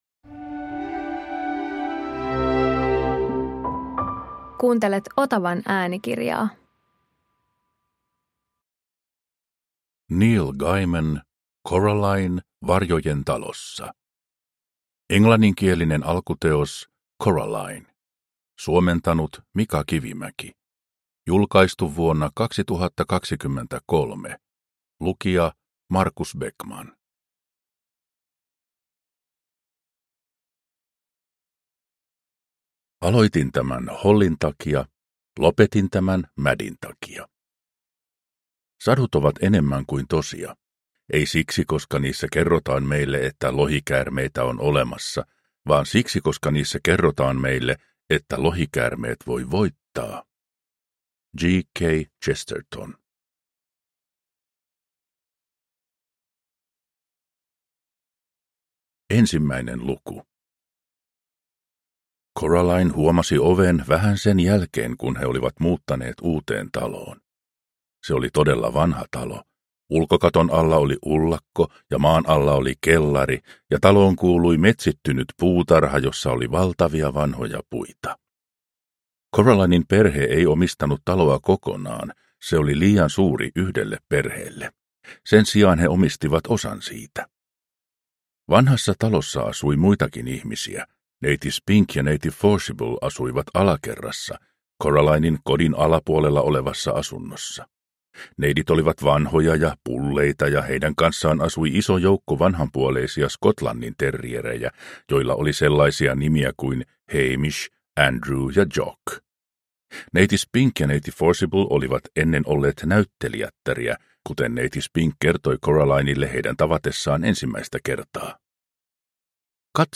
Coraline varjojen talossa – Ljudbok – Laddas ner